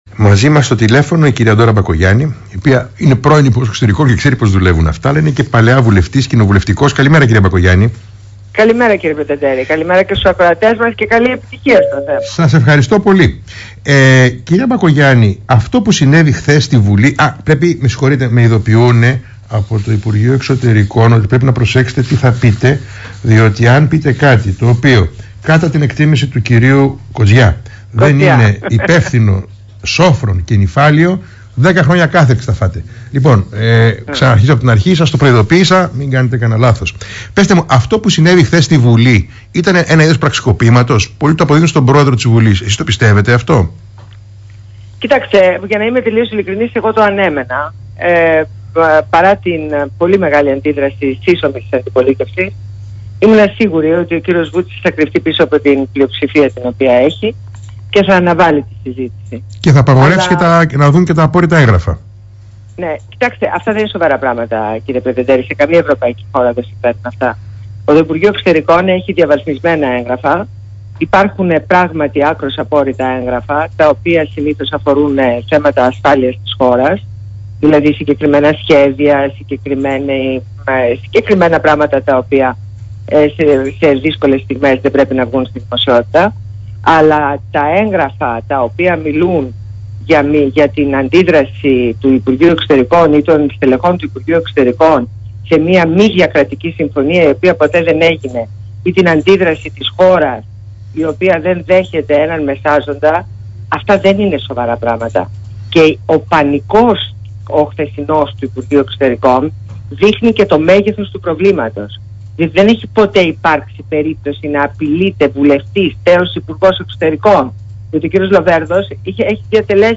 Συνέντευξη στο Θέμα radio 104,6